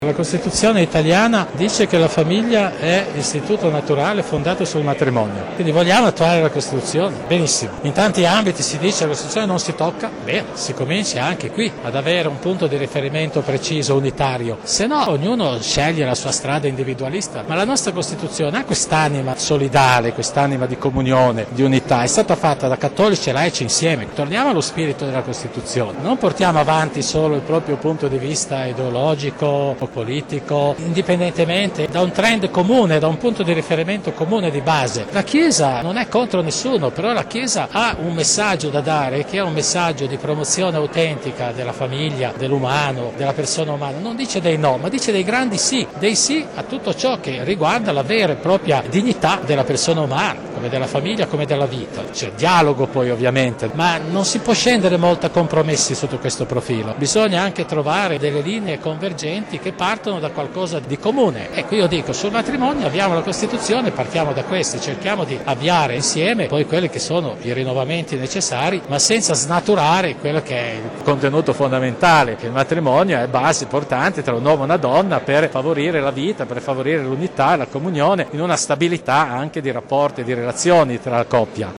Mons. Nosiglia su matrimonio a margine visita Palazzo civico